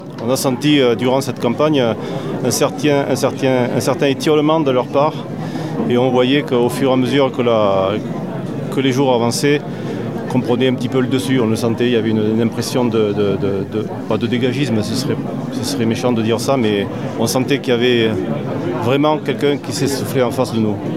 Quelques minutes après l’annonce des résultats, devant une foule venue assister au dépouillement final, le vainqueur, Patrice Saint-Léger a réagi aux résultats.